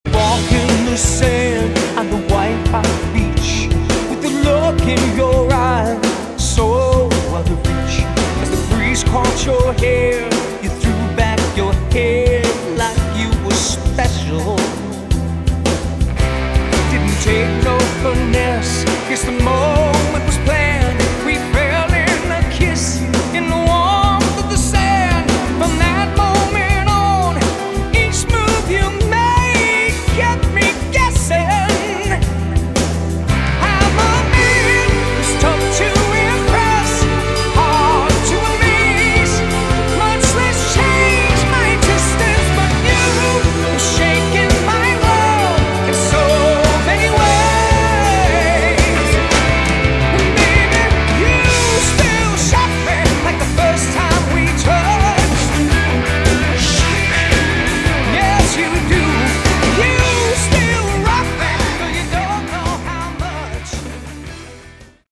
Category: AOR
lead vocals
guitars
bass guitar
drums & percussion
keyboards